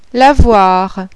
LAVOIR.wav